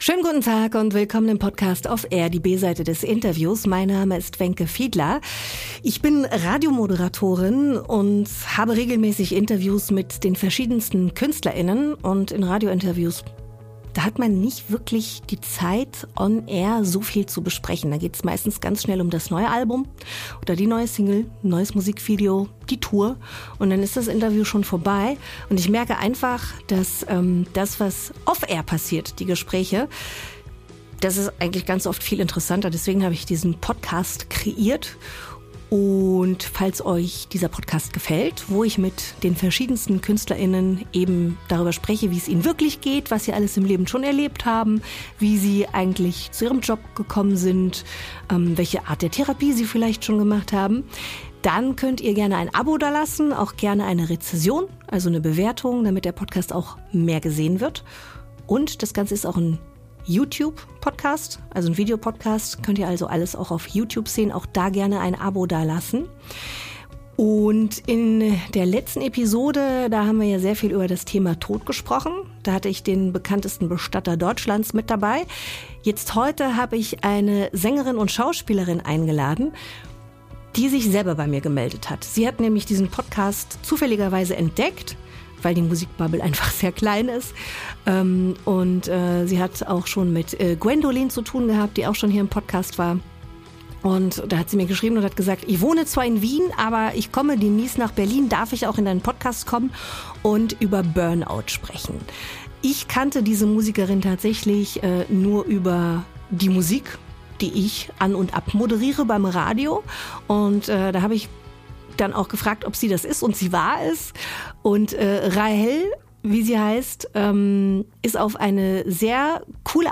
Ein wertvolles, einfühlsames Gespräch über die Kunst, das Leben zu meistern, oft mit einem Lächeln und einer Prise Humor.